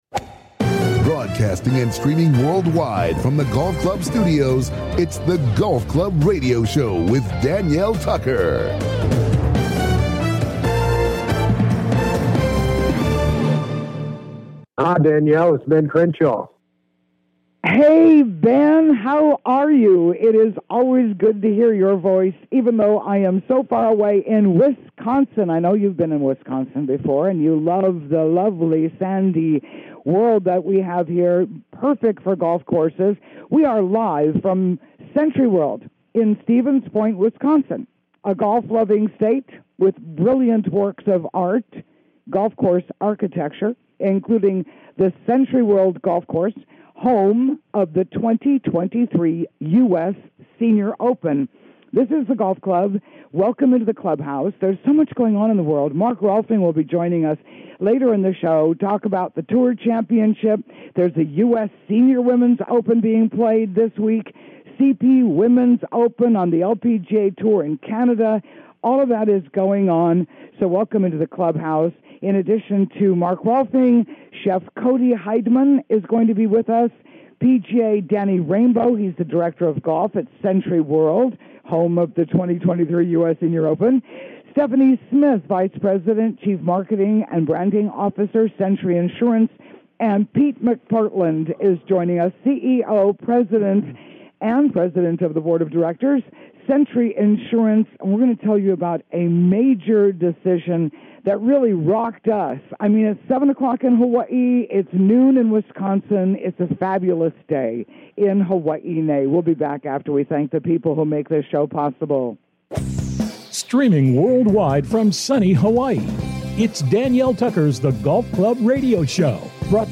Headliner Embed Embed code See more options Share Facebook X Subscribe In The Clubhouse: LIVE FROM SENTRYWORLD IN STEVENS POINT, WISCONSIN, A GOLF LOVING STATE WITH BRILLIANT WORKS OF ART INCLUDING THE SENTRY WORLD COURSE…